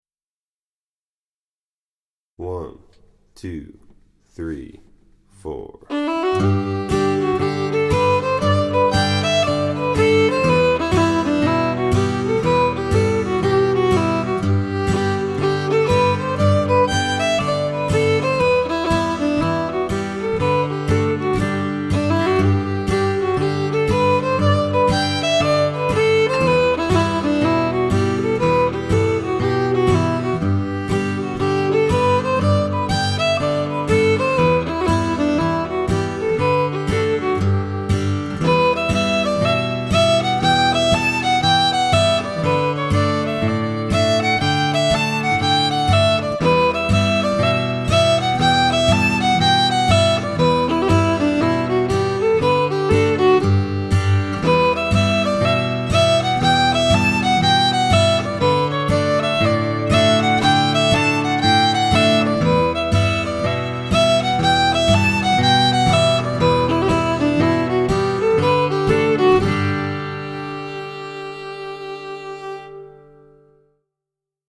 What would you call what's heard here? Voicing: Violin Method